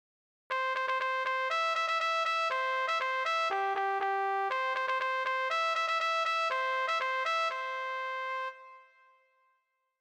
曲 号音
トランペット独奏